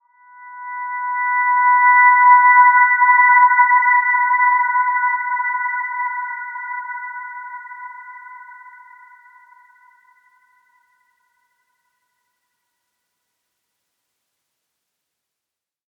Dreamy-Fifths-B5-mf.wav